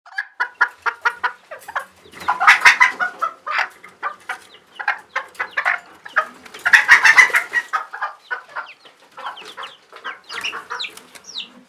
Chicken Coop Inside Sounds